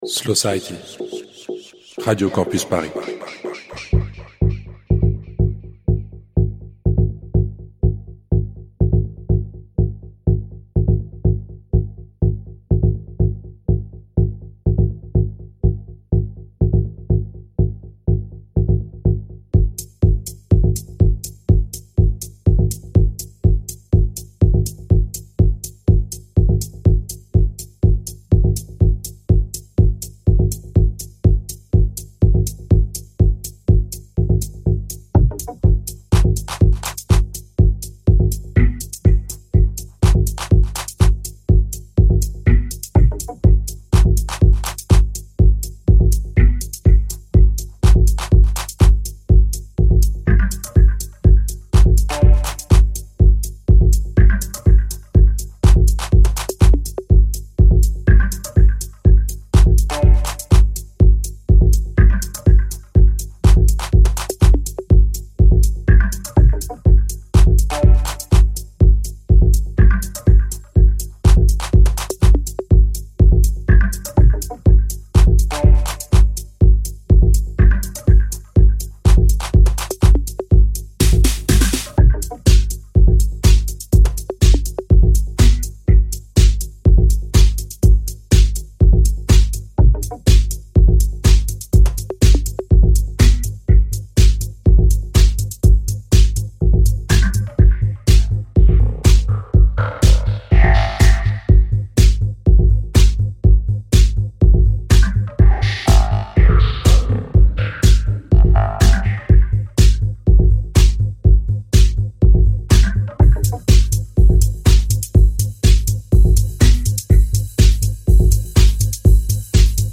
la jonction entre house et techno
sonorités à la fois mélancoliques et galvanisantes